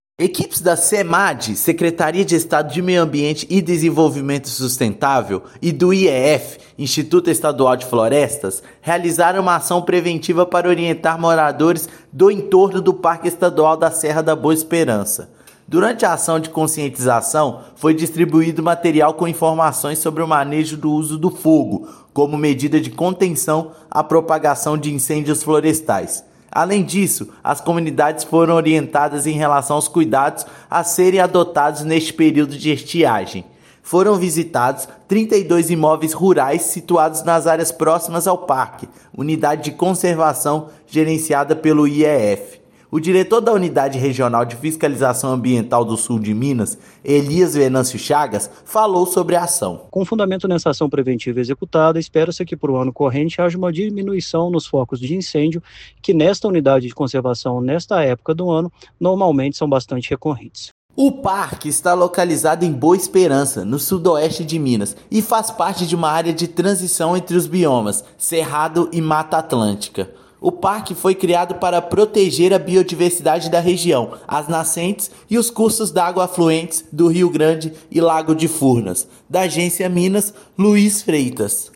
Objetivo foi conscientizar acerca das limitações legais associadas ao manejo do fogo. Ouça matéria de rádio.